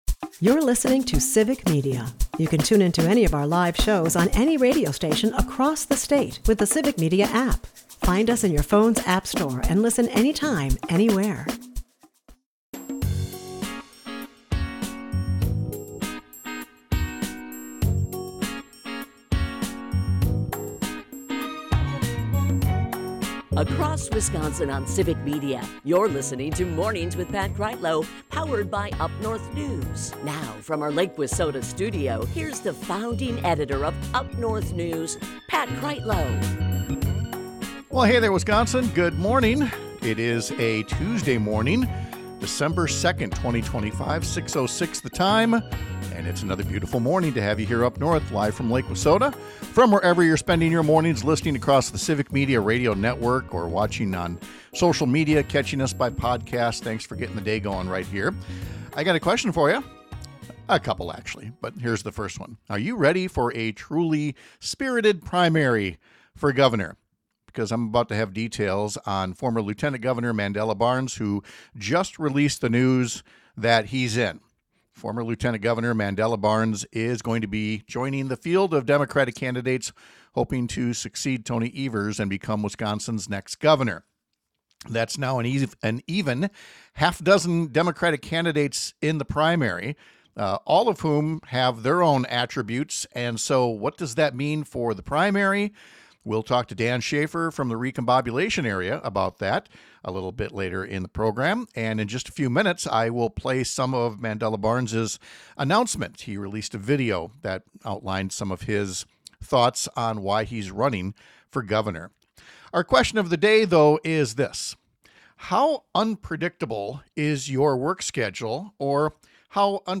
This morning, former Lt. Gov. Mandela Barnes formally announced his entry into the 2026 race to be Wisconsin’s next governor. He joins the current lieutenant governor, a county executive, a couple of legislators, and others running in the Democratic field. We’ll hear Barnes’ opening pitch to do things the “Wisconsin Way” rather than Donald Trump’s “Washington Way.”